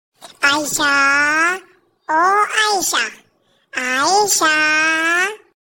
Goat Calling